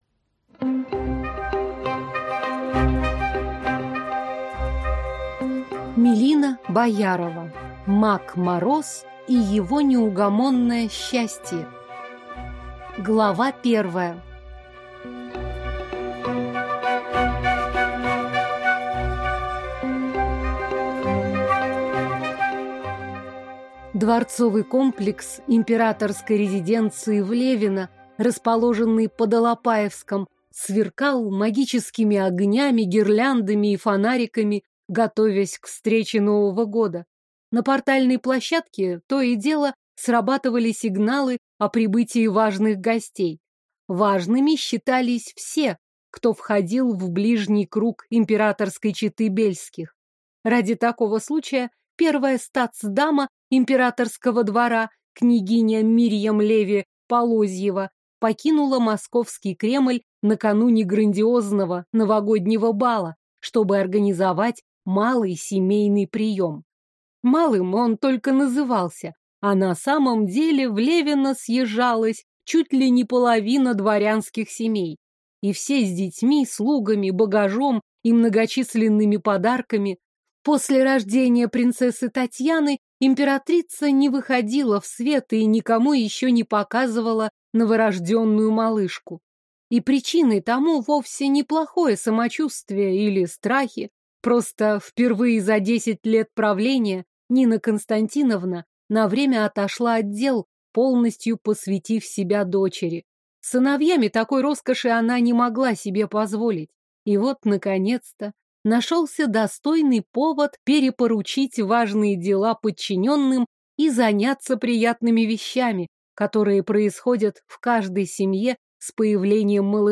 Аудиокнига Маг Мороз и его неугомонное счастье | Библиотека аудиокниг